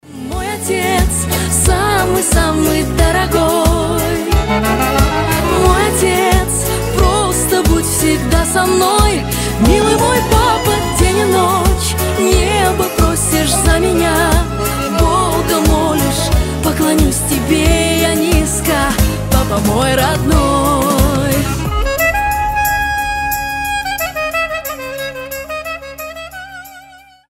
Рингтоны шансон